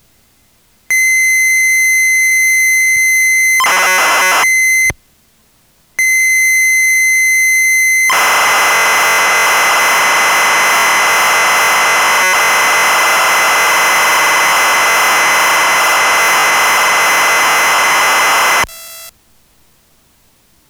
Having a listen to them, they don't appear to be the best quality recording, I'm wondering if you could have a go at re-recording them at a lower volume/with less interference?
P.s. I did notice that file A is recorded at a lower volume, which might explain why it doesn't work for you and file B does.
They weren't working for me at a lower capture volume, either, so I tried capturing them as loud as I could without any visible clipping.